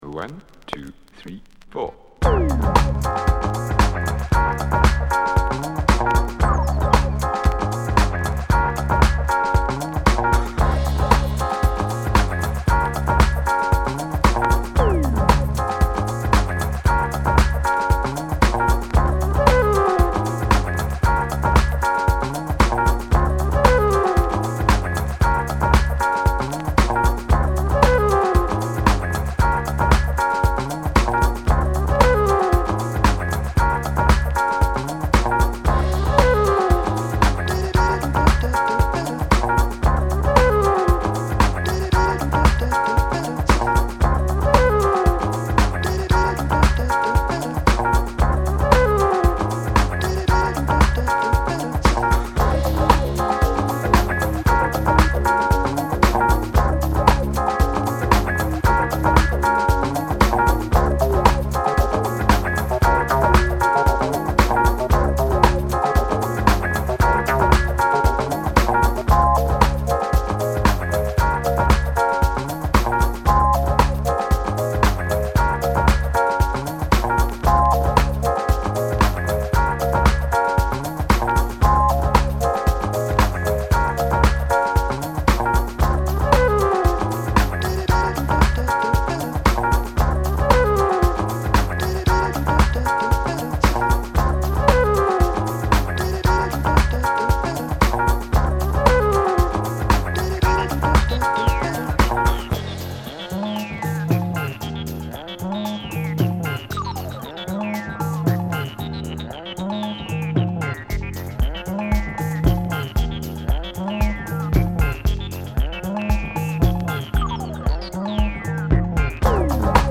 早すぎたインディー・ブレイクビーツ鬼キラー！